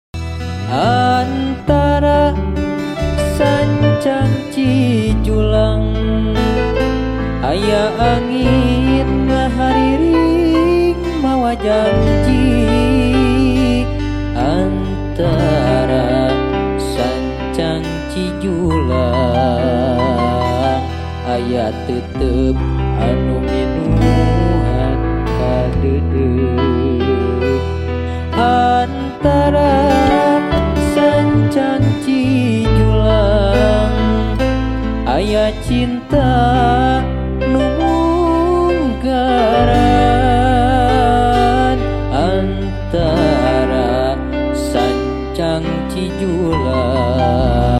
LIVE ACOUSTIC COVER
Gitar
Gitar Bass
Keyboard